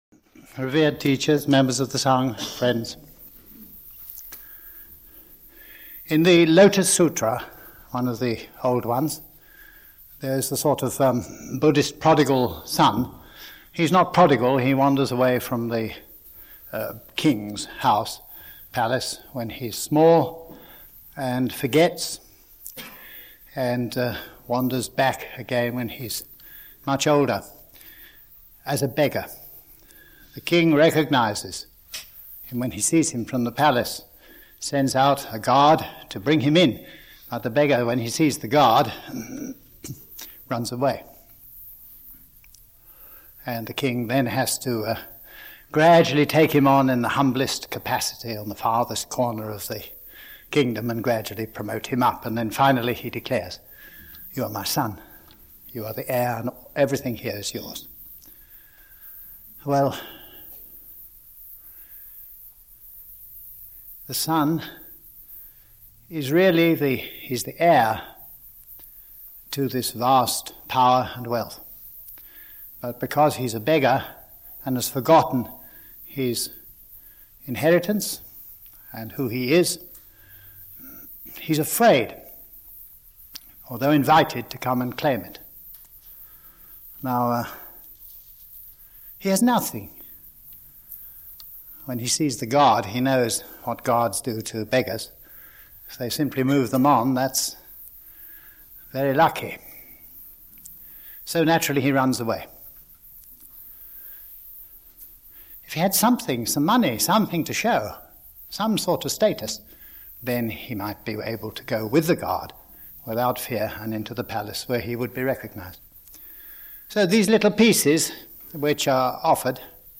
The title of the talk is The Stone Sermon.